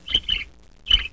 Blackbird Sound Effect
Download a high-quality blackbird sound effect.
blackbird-1.mp3